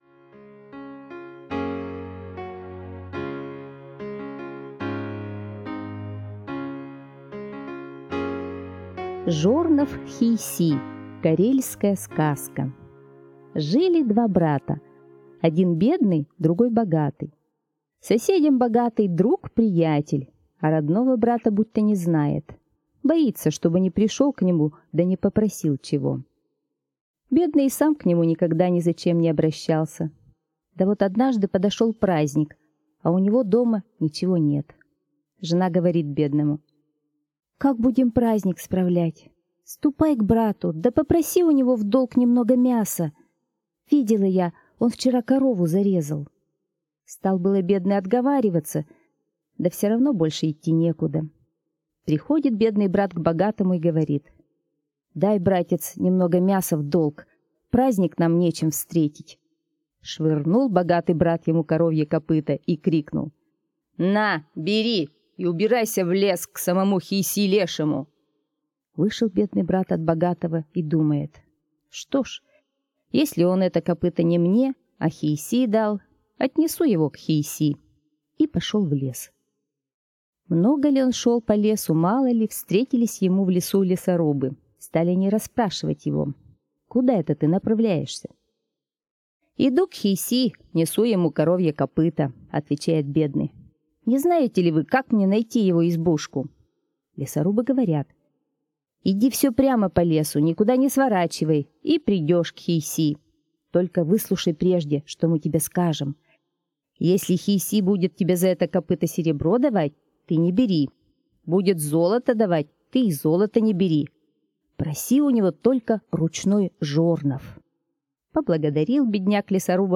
Жернов Хийси - карельская аудиосказка - слушать онлайн